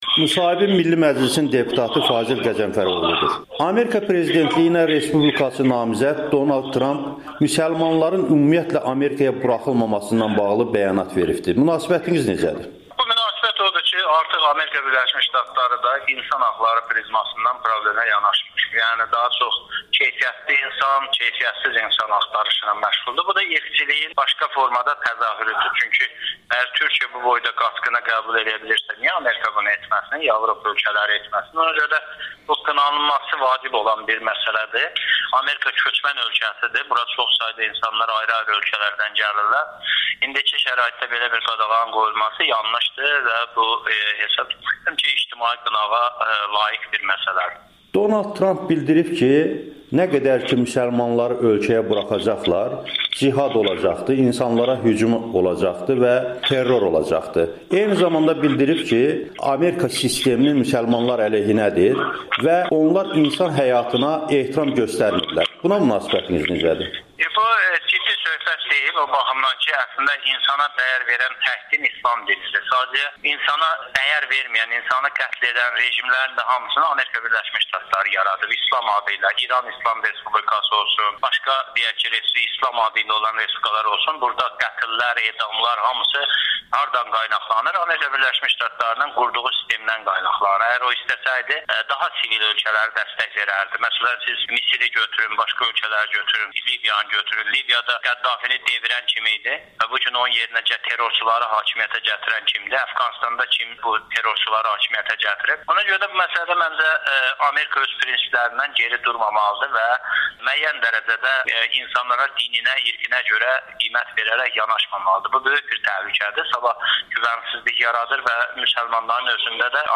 Milli Məclisin deputatı Amerikanın Səsinə müsahibəsində respublikaçı namizədin müsəlmanların ABŞ-a buraxılmaması barədə bəyanatını şərh edib
Fazil Qəzənfəroğlunun Amerikanın Səsinə müsahibəsi